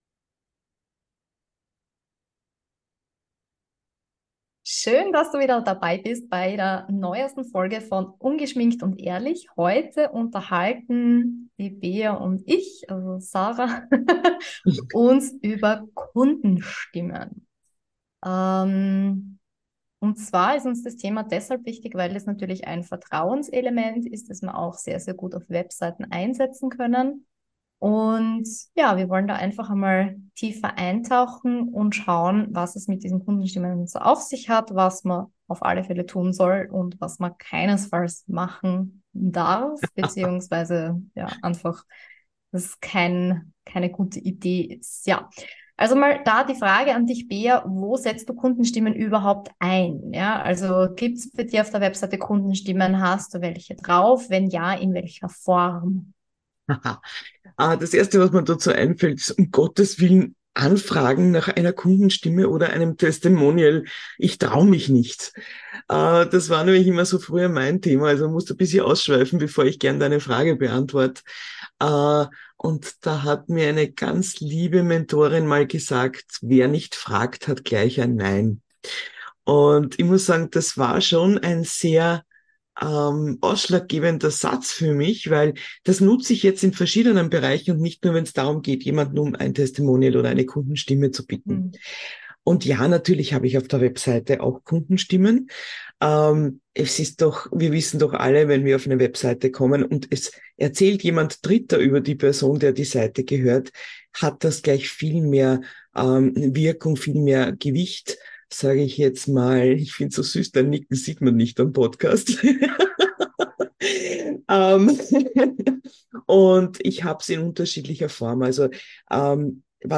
Wir plaudern mal wieder frei und fröhlich von der Leber weg und geben Tipps zur Handhabung…